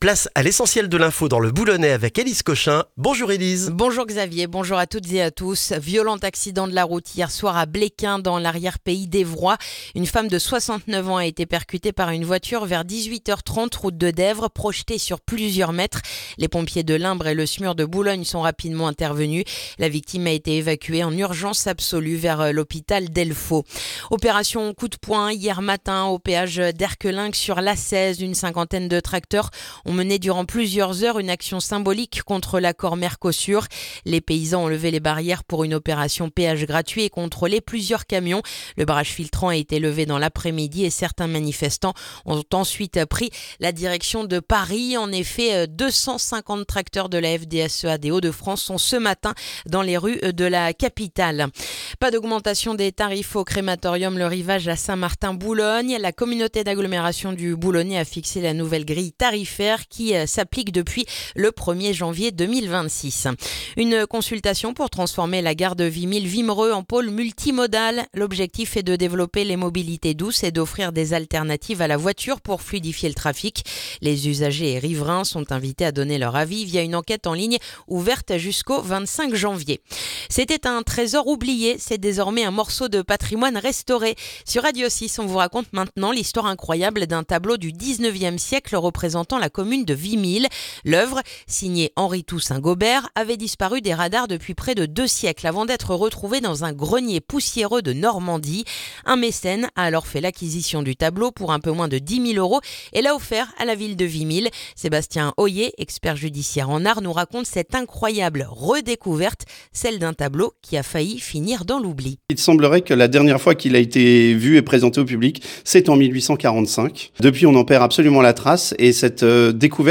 Le journal du mardi 13 janvier dans le boulonnais